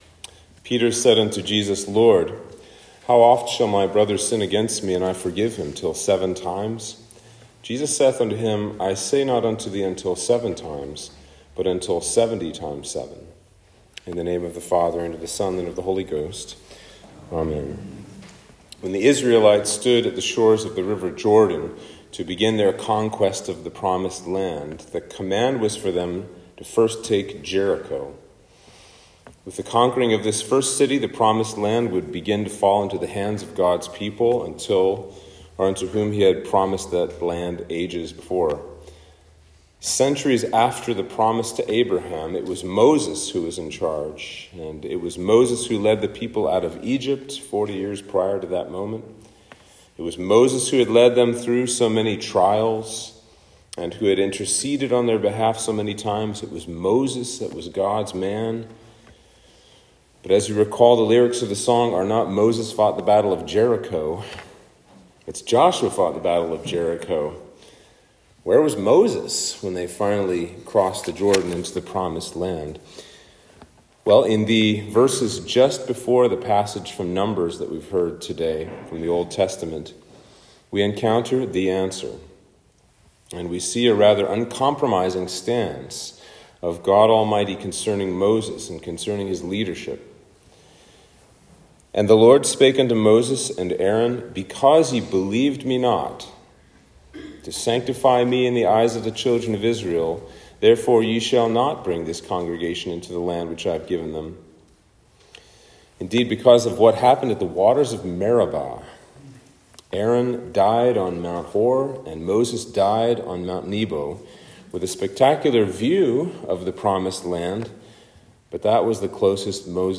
Sermon For Trinity 22